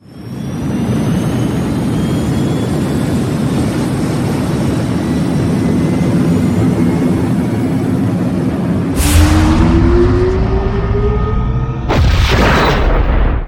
launch2.ogg